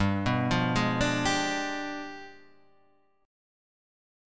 G 7th Flat 9th